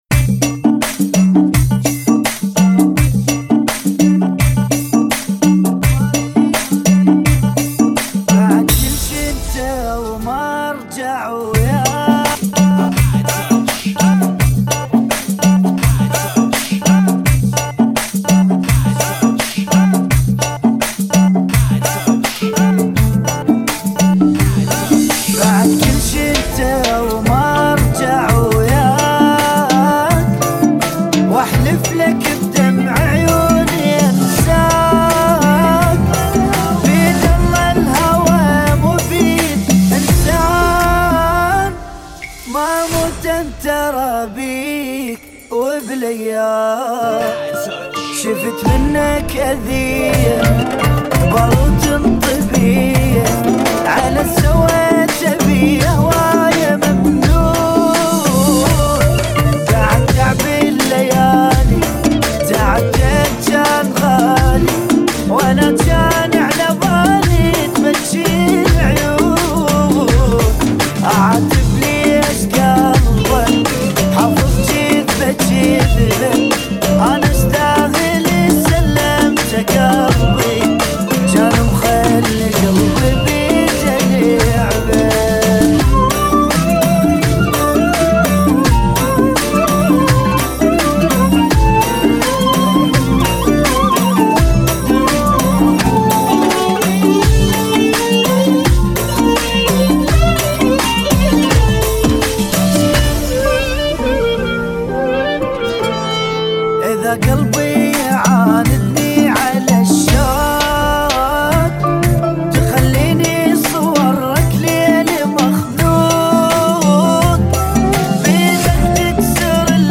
84 bpm